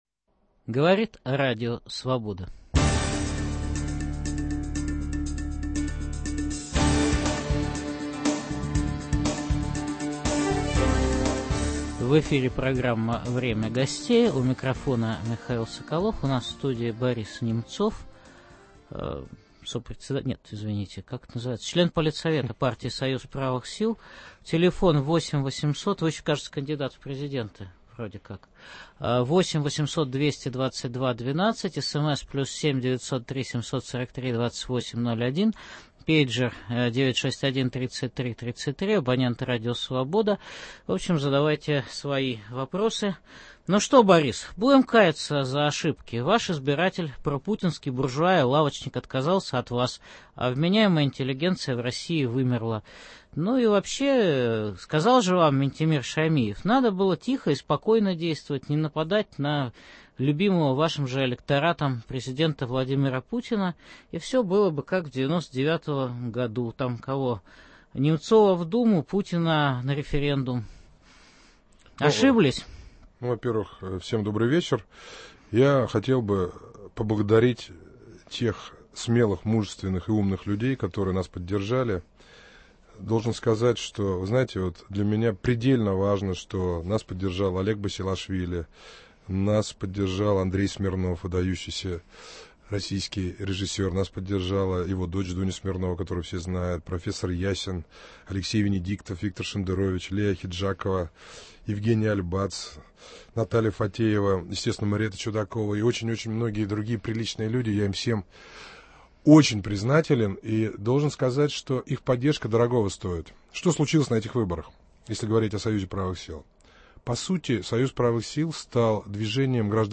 лидер партии СПС Борис Немцов.